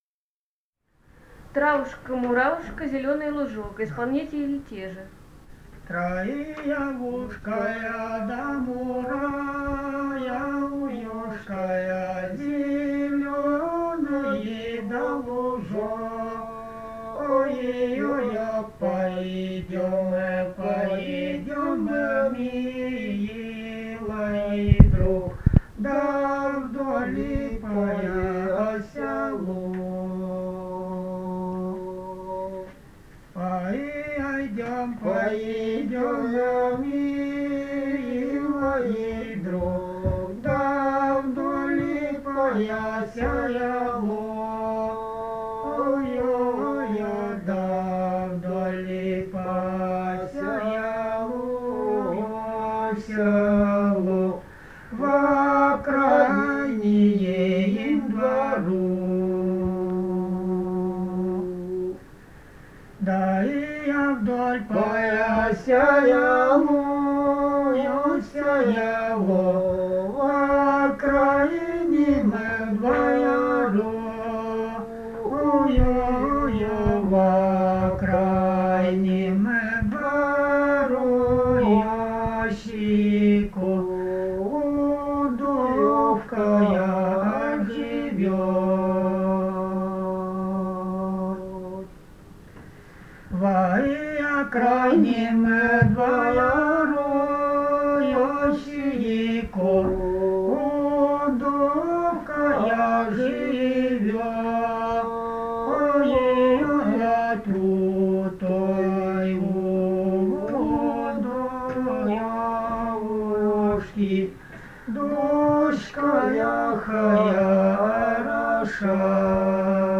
Этномузыкологические исследования и полевые материалы
Ставропольский край, с. Бургун-Маджары Левокумского района, 1963 г. И0718-03